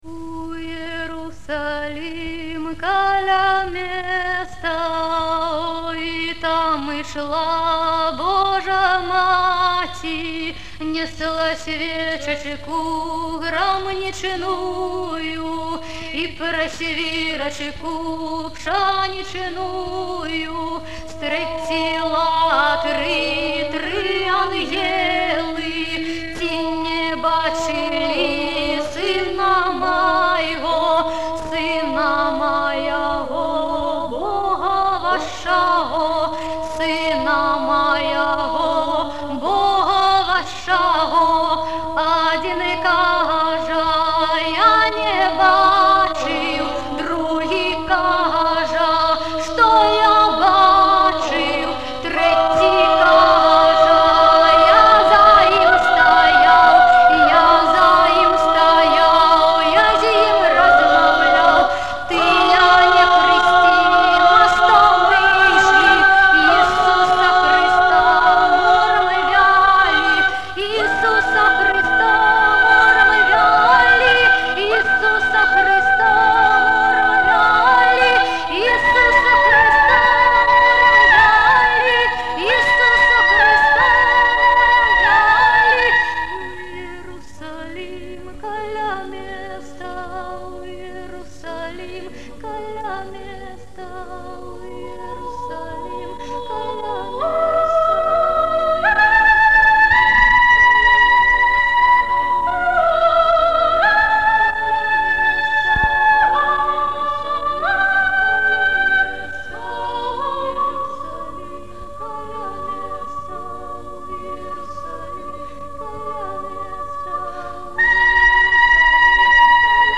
"Каралёначкі" сьпяваюць "У Ерусалім каля места". Архіўны запіс.